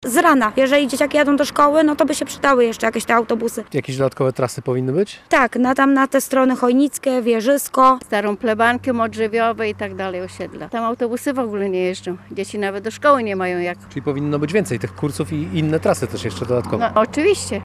Mieszkańcy Kościerzyny od dawna sygnalizowali, że komunikacja miejska wymaga zmian:
sonda-komunikacja.mp3